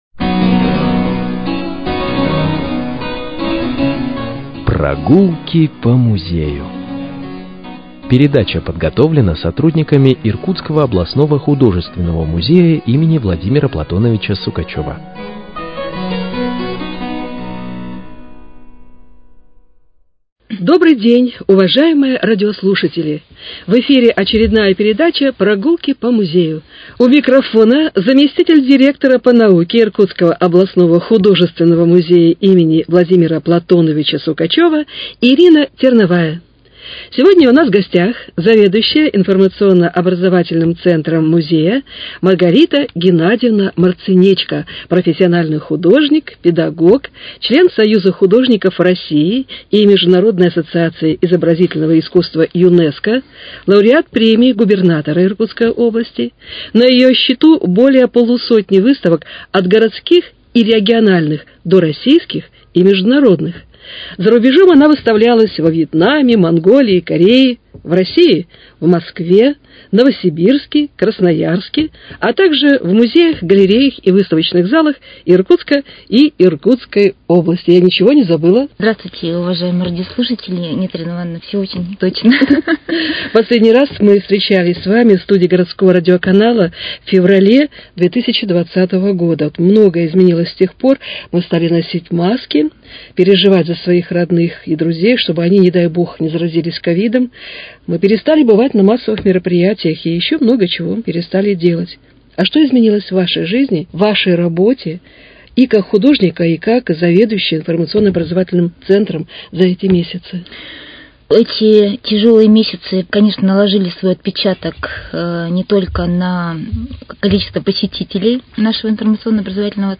Прогулки по музею: Беседа с заведующей Информационно